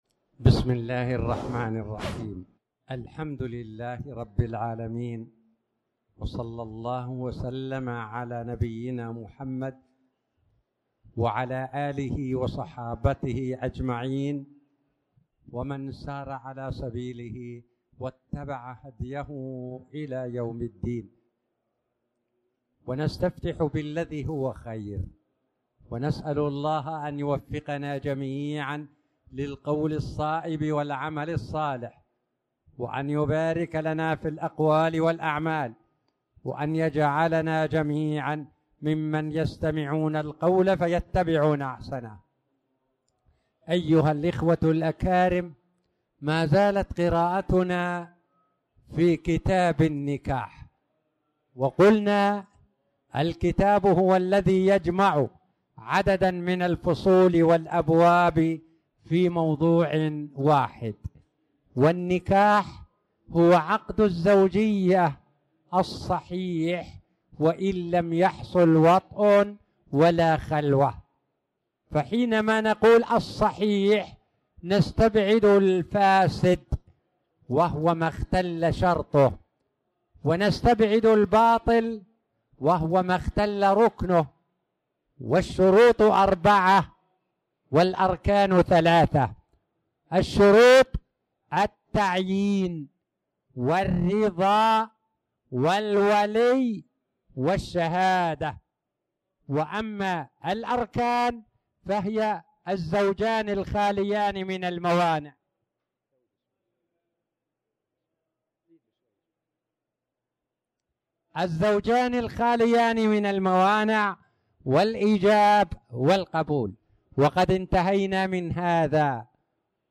تاريخ النشر ٤ جمادى الأولى ١٤٣٨ هـ المكان: المسجد الحرام الشيخ